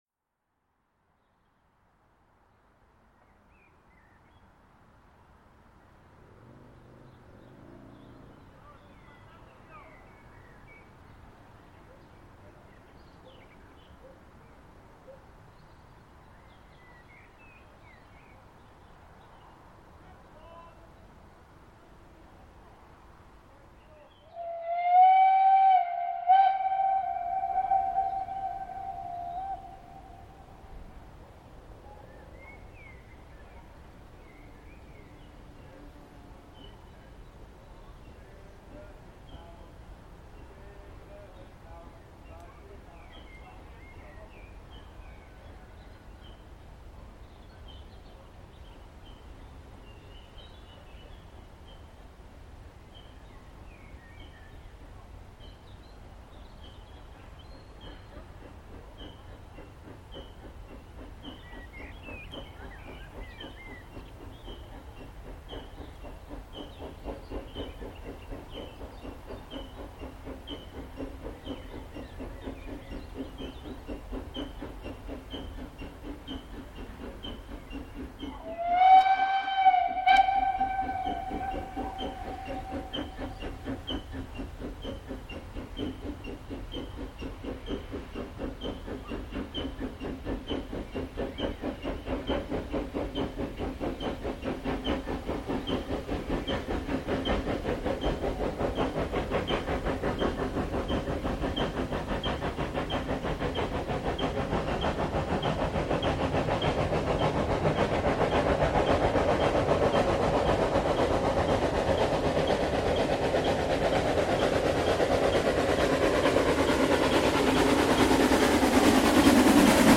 Leider wird dieses Geräusch parallel zu besserer und lauterer Fahrweise ebenfalls viel lauter.
An diesem Tag wurden aber keine Wagen abgehängt, so dass auch wieder die Last aller 7 Wagen noch mal guten Sound ermöglichte:
Lok 11  mit Regelzug von Kupferkammerhütte→Benndorf, aufgenommen kurz hinter der Ausfahrt von Kupferkammerhütte, um 15:45h am 29.05.2025.   Hier anhören: